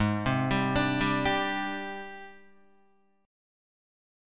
AbM#11 Chord